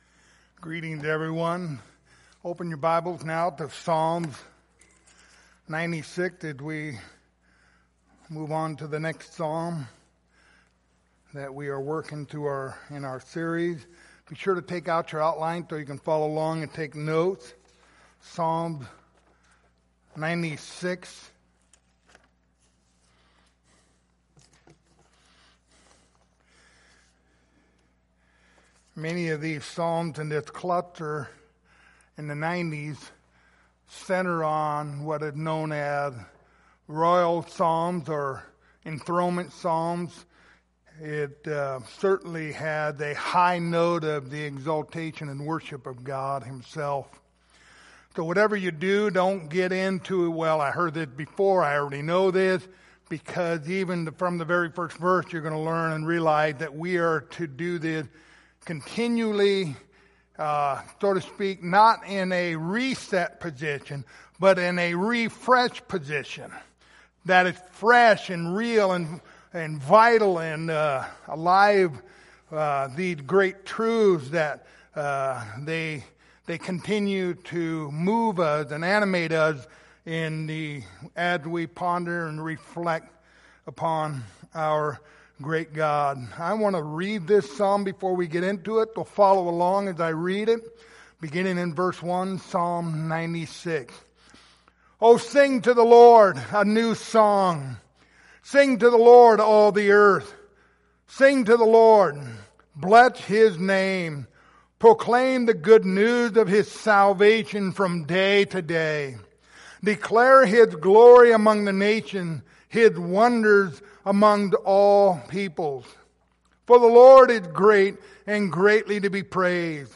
Passage: Psalms 96:1-13 Service Type: Sunday Morning